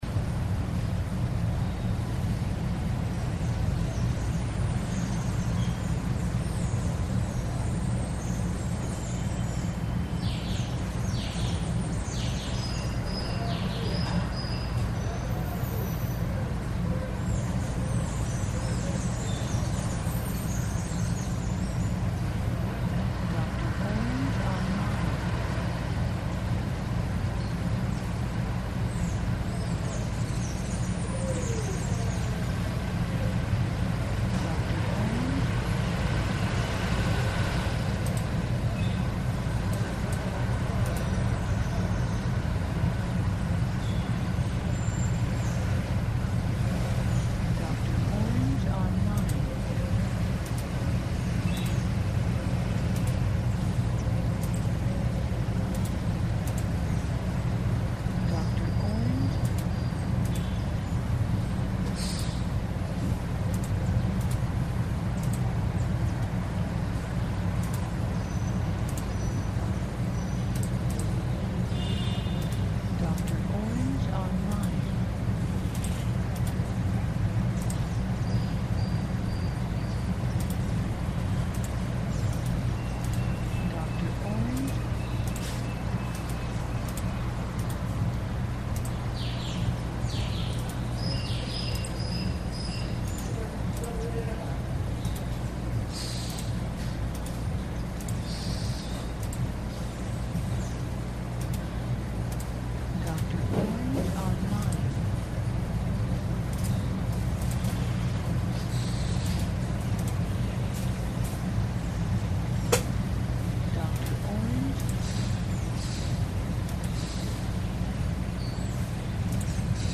Ambiente Ambisónico Exterior Casa Jardín Laptop Trabajando
INTERIOR CASA-JARDIN, MOUSE PC TRABAJANDO, PAJARILLOS, BOCINA LEJANA, VENDEDOR DE GAS LEJANO, PERSONA HABLA.
Archivo de audio AMBISONICO, 96Khz – 24 Bits, WAV.